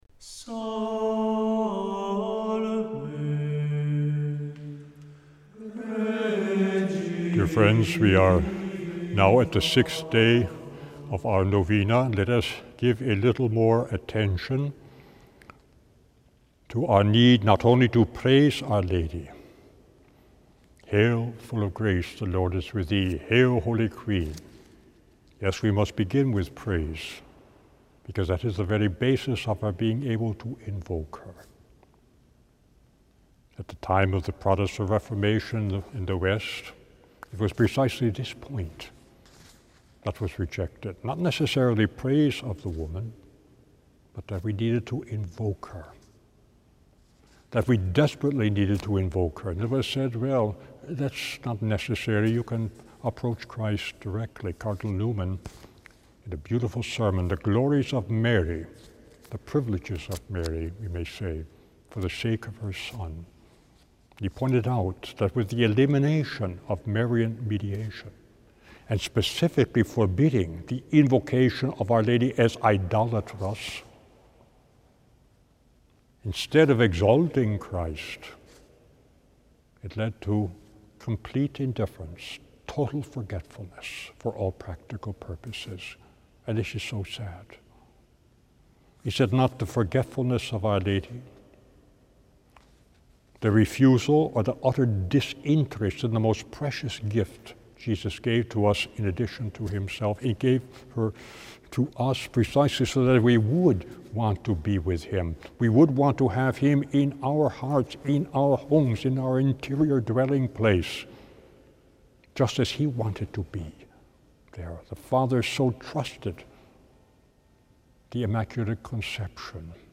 Each day, from the 6th up to the 14th, he gives a short reflection on Our Lady followed by a prayer.